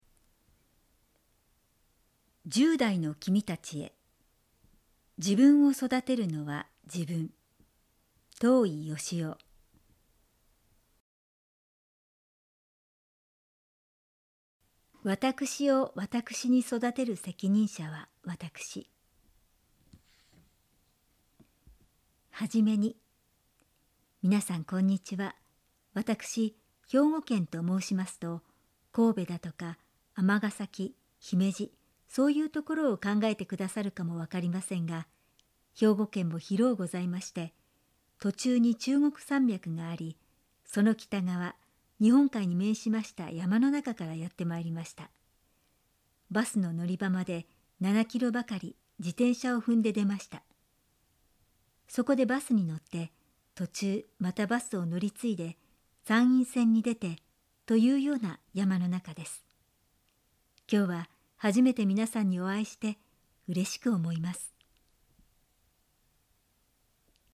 致知出版社の人気書籍を、プロが朗読したオーディオブックが新登場。
オーディオブック①